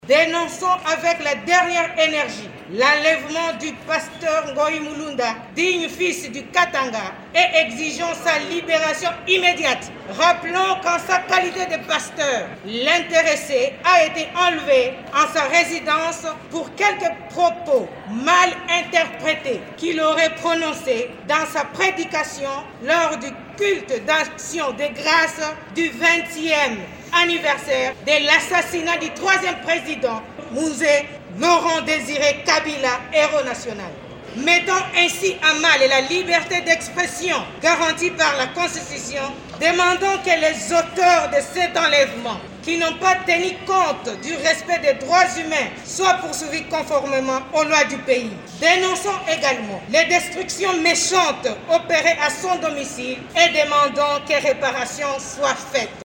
Vous pouvez écouter l’extrait de cette déclaration lue par Mme Liliane Ngoy Mani en cliquant ici :